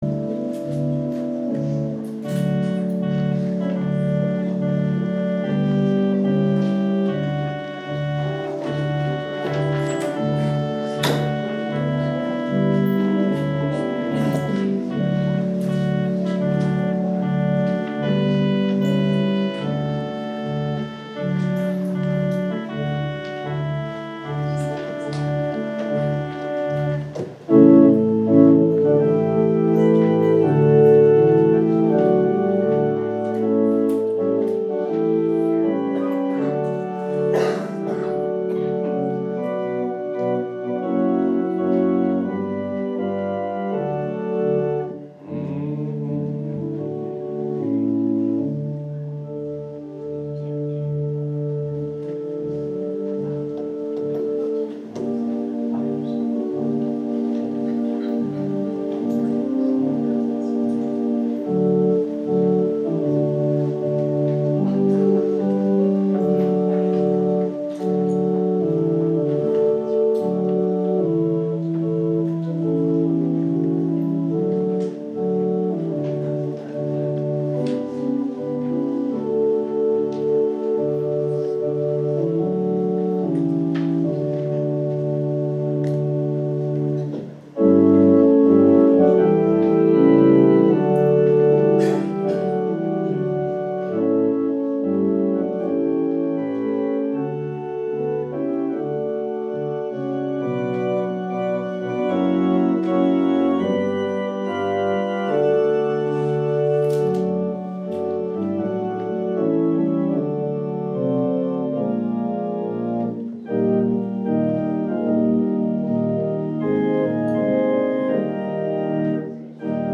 Deep Run OFWB Church Sermons